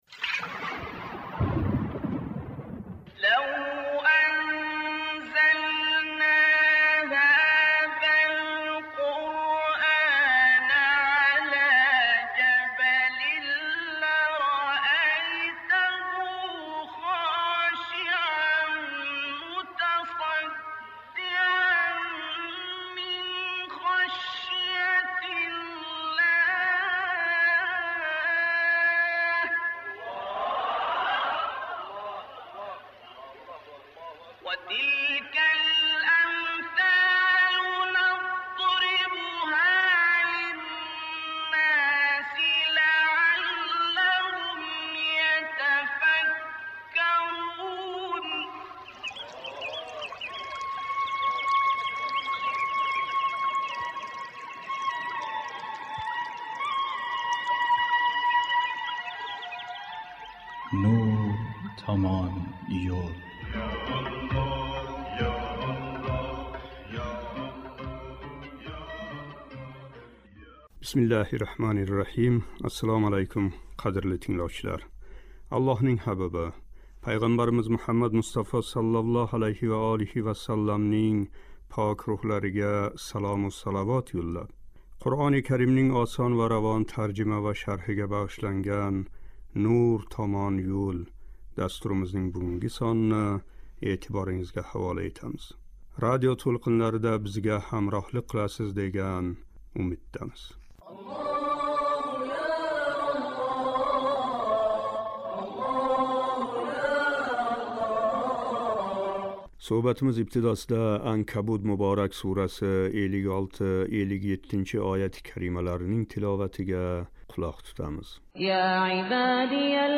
Суҳбатимиз ибтидосида “Анкабут” муборак сураси 56-57--ояти карималарининг тиловатига қулоқ тутамиз.